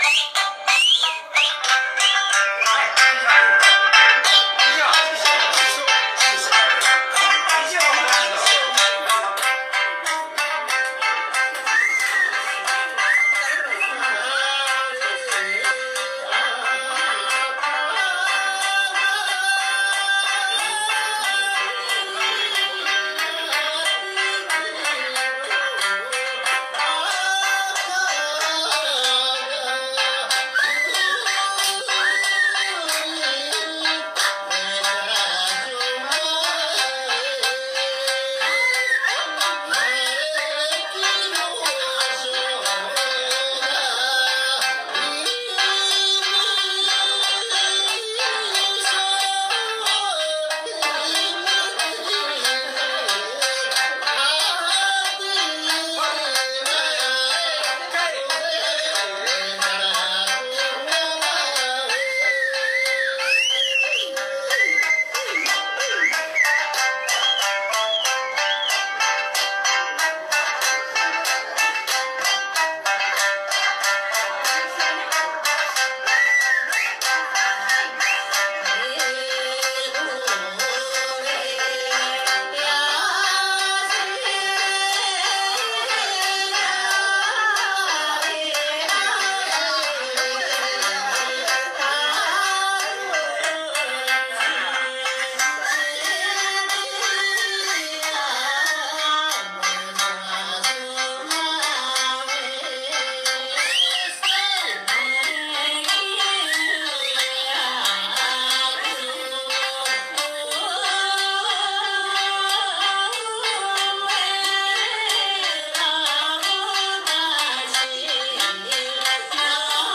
OPENマイクで、飛び入り♬ 次々とお祝いライブをしてくださいました。
シマ唄にピッタリな声の持ち主。